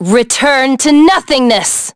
Lucikiel_L-Vox_Skill1.wav